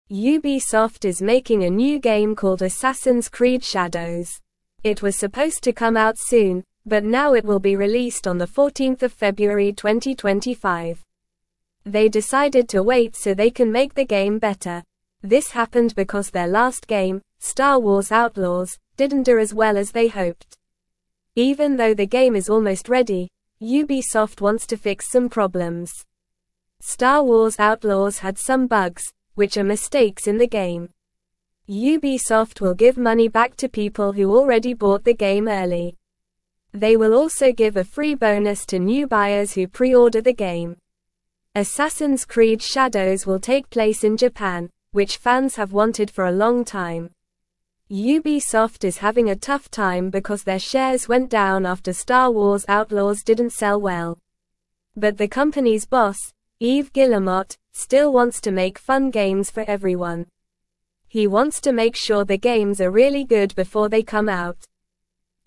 Normal
English-Newsroom-Lower-Intermediate-NORMAL-Reading-Ubisoft-delays-new-game-to-make-it-better.mp3